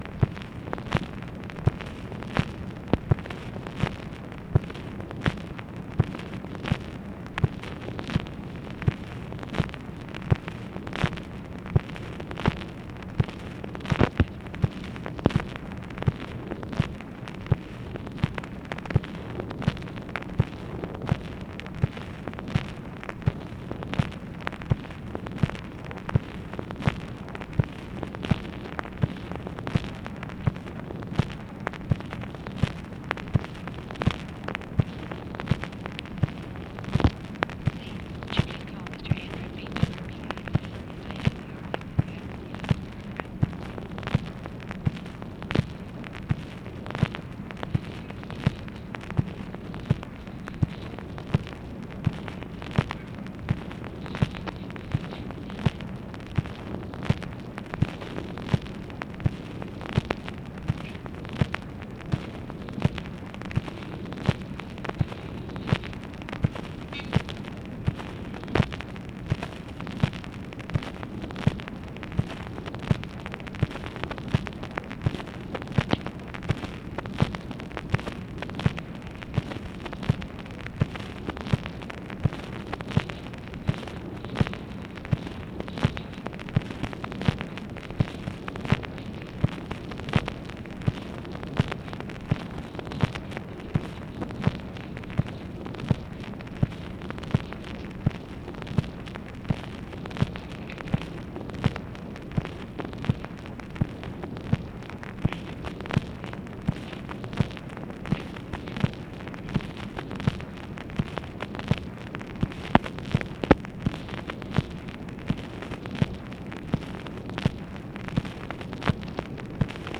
Conversation with TELEPHONE OPERATOR and OFFICE NOISE